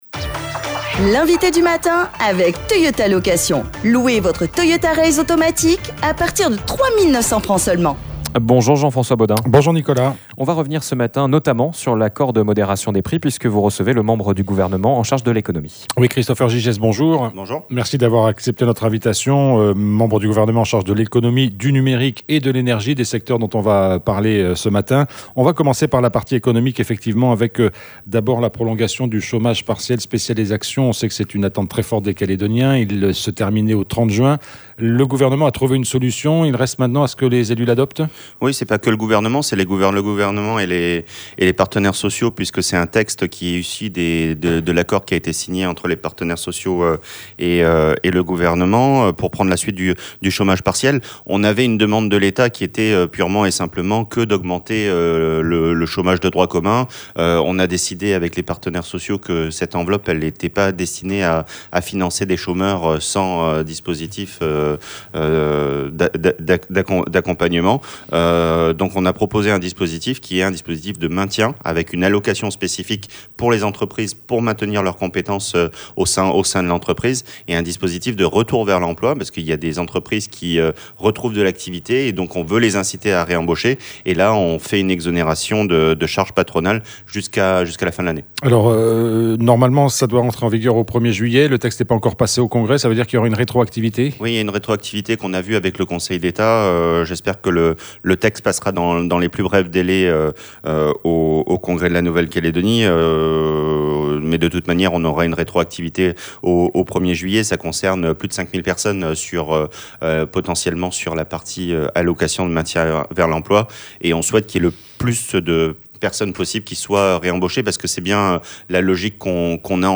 Christopher Gygès, membre du Gouvernement, était notre invité ce vendredi matin. Nous sommes revenus avec lui sur l'accord interprofessionnel de modération des prix en 2025. L’occasion aussi de dresser aussi avec lui un bilan de la participation des start-ups calédoniennes au salon Viva Tech et de parler du projet de construction d’une centrale de stockage d’électricité, dont le financement est porté par une société privée à hauteur de 9 milliards de Francs.